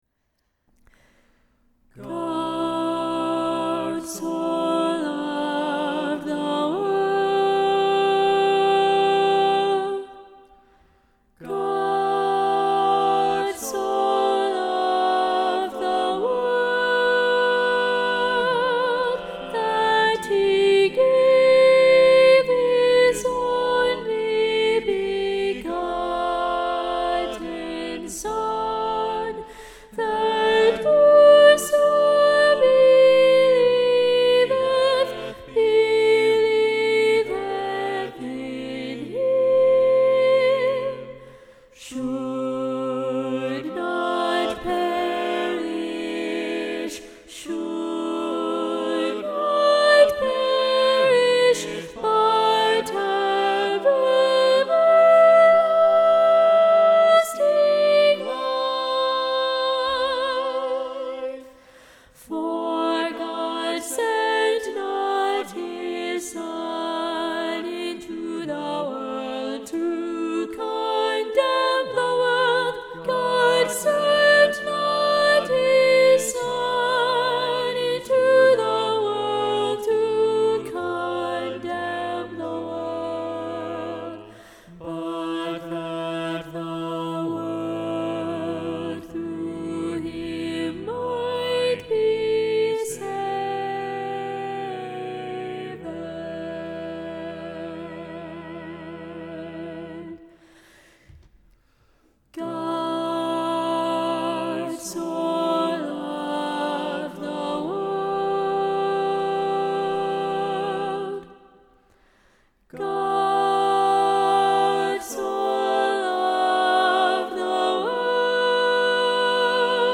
God so Loved the World SATB – Soprano Predominant – John StainerDownload